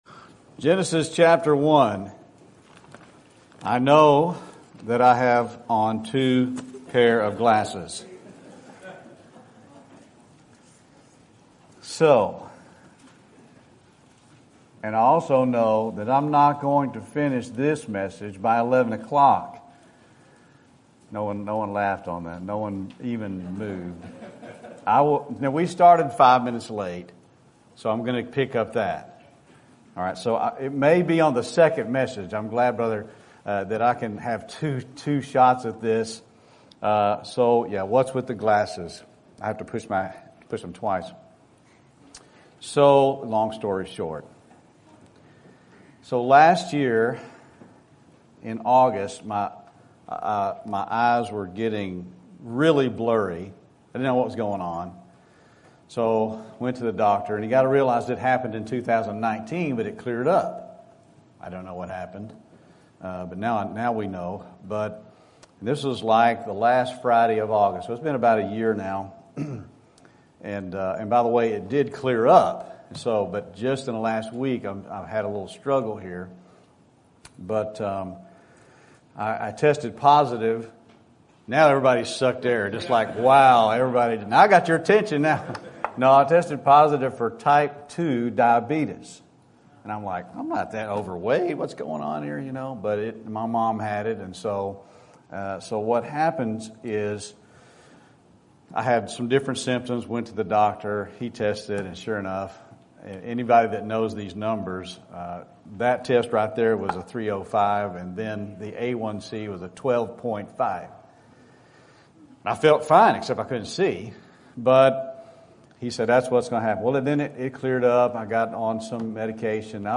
Sermon Topic: Youth Conference Sermon Type: Special Sermon Audio: Sermon download: Download (12.36 MB) Sermon Tags: Genesis Gender Marriage Design